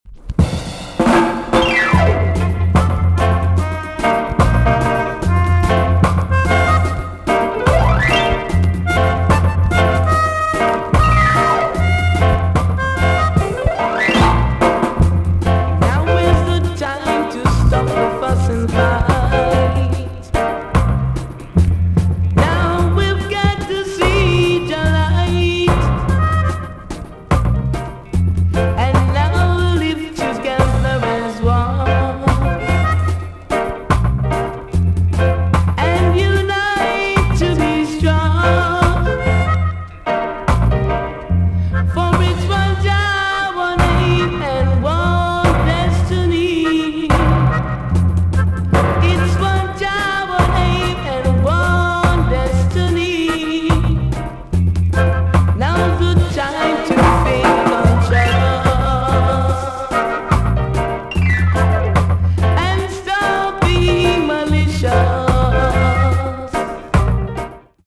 EX- 音はキレイです。
WICKED KILLER ROOTS TUNE!!
REAL ROCK RIDDIM